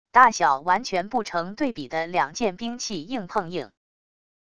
大小完全不成对比的两件兵器硬碰硬wav音频